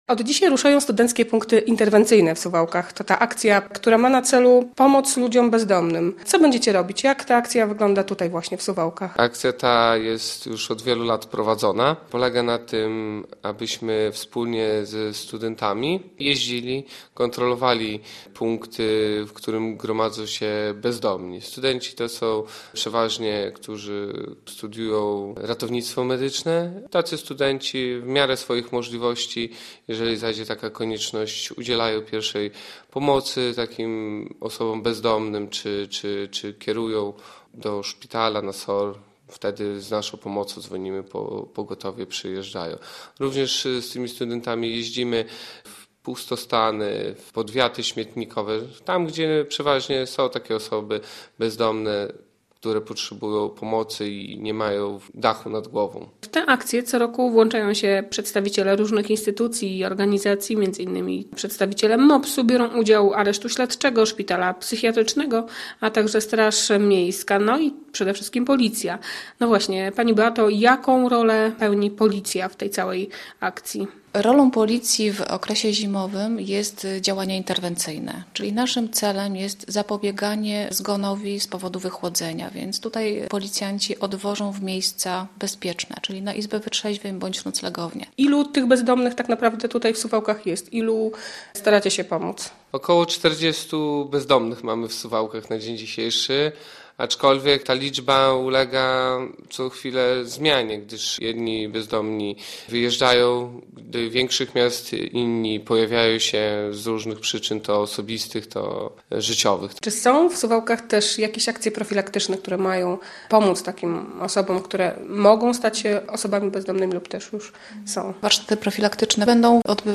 Władze Suwałk dopłacą mieszkańcom miasta do wymiany pieców - relacja